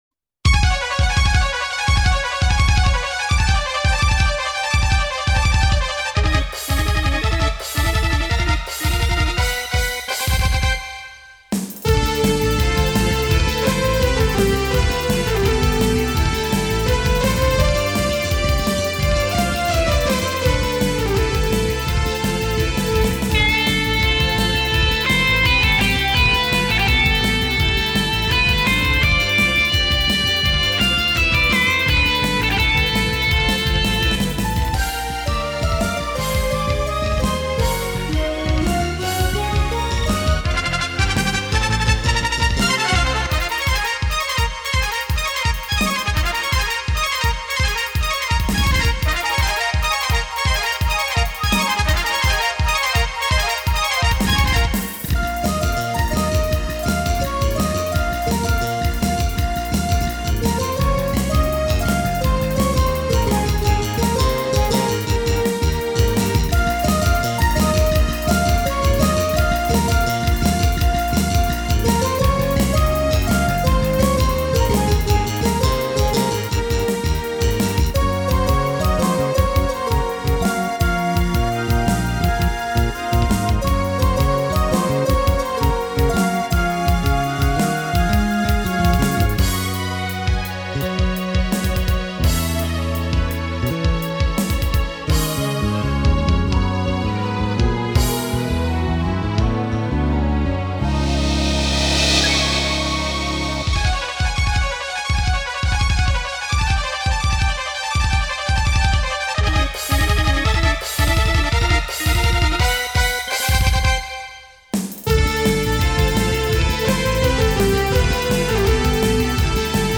エレクトーンでゲーム音楽を弾きちらすコーナー。